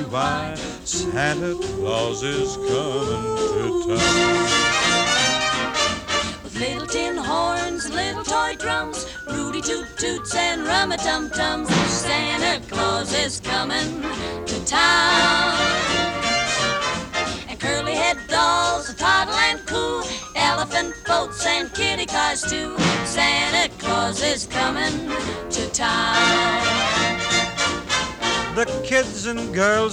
• Holiday